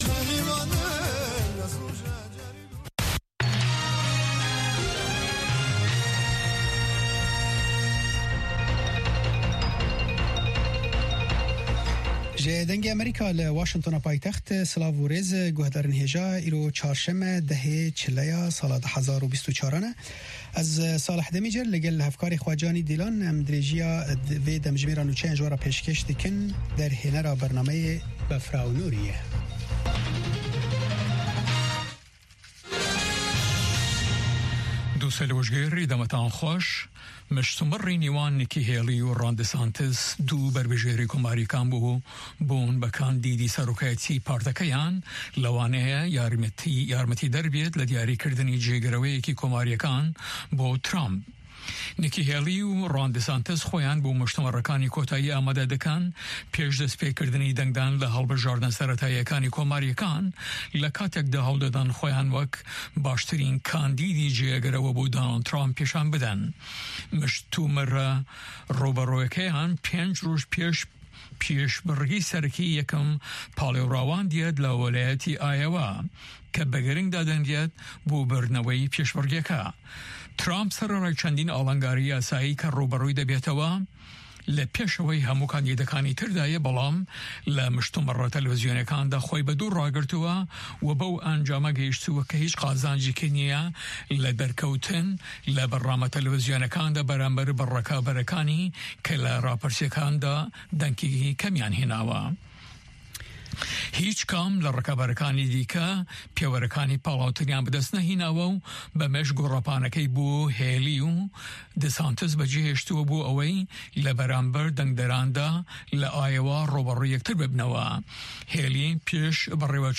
Nûçeyên 3’yê paşnîvro